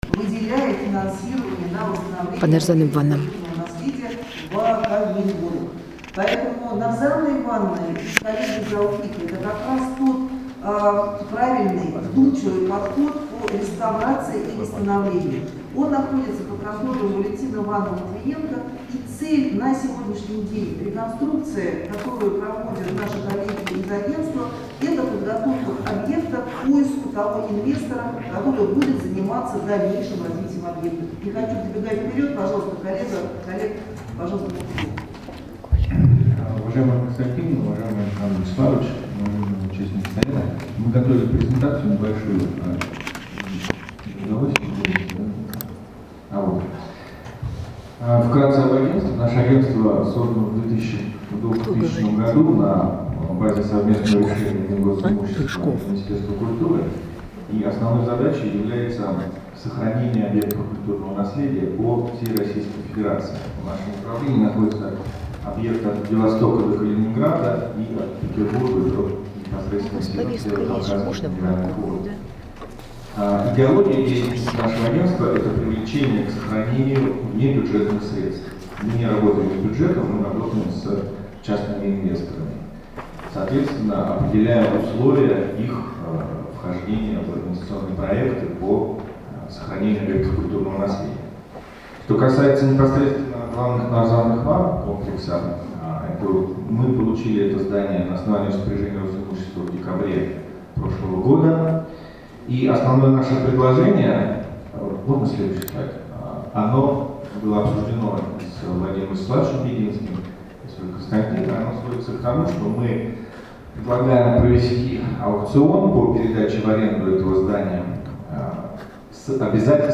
ОЧЕРЕДНОЕ ЗАСЕДАНИЕ ОБЩЕСТВЕННОГО СОВЕТА ПРИ РОСИМУЩЕСТВЕ
С детальным докладом о проводимых работах выступил руководитель ФГБУК «Агентство по управлению и использованию памятников истории и культуры Олег Рыжков.